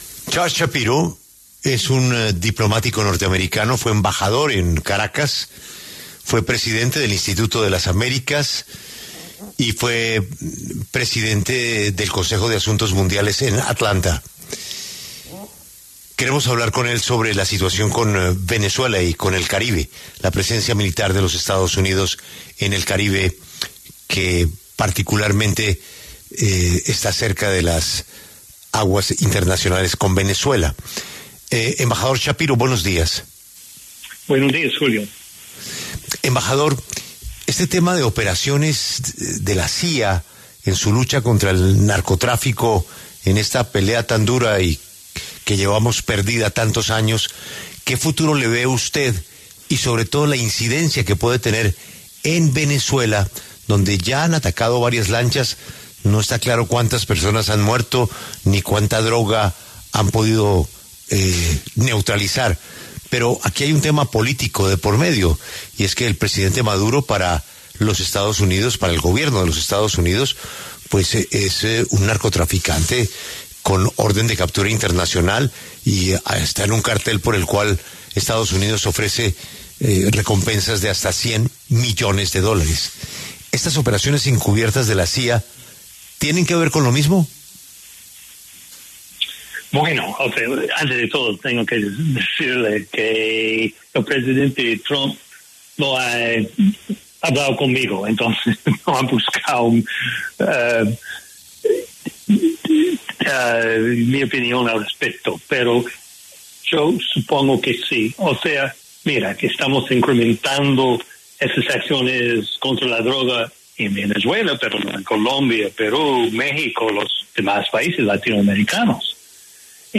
Charles Shapiro, diplomático estadounidense y exembajador, conversó con La W sobre la tensión que se fortalece entre los Gobiernos de EE.UU. y Venezuela.